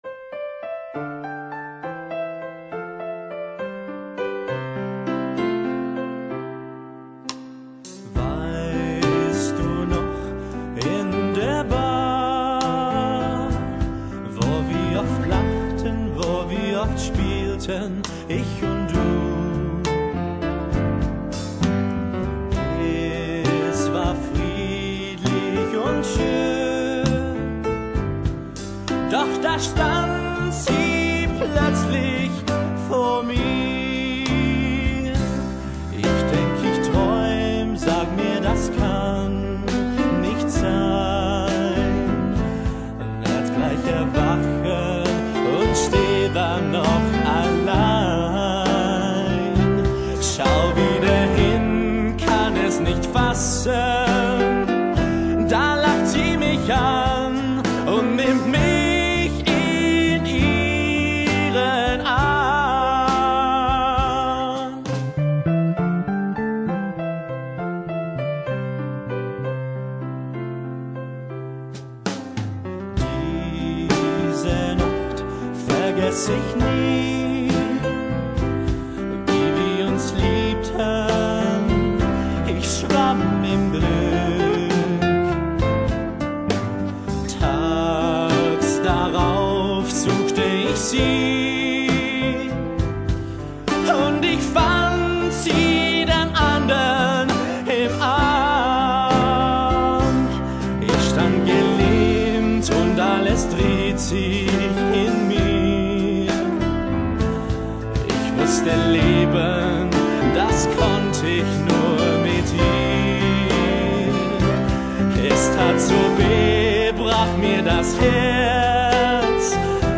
Die Western-Musical-Comedy von Christina Bichsel